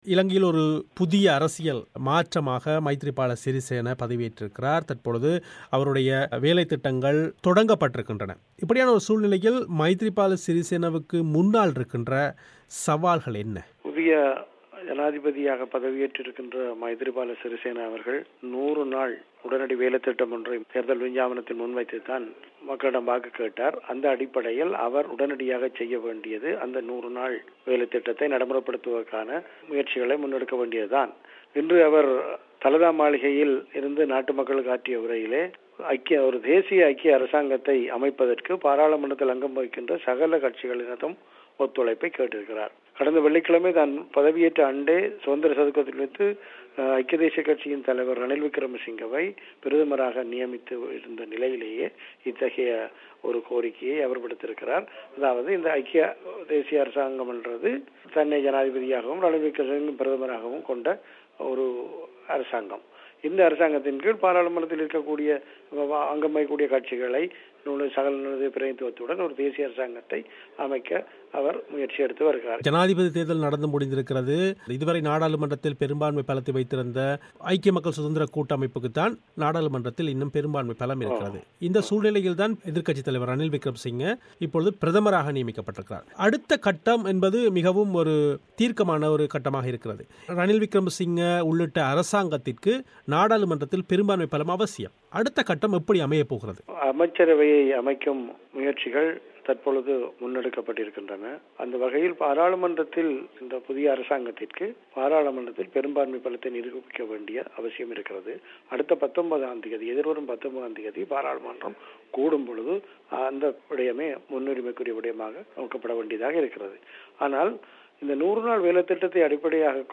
ஆய்வுச் செவ்வி